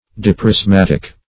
Diprismatic \Di`pris*mat"ic\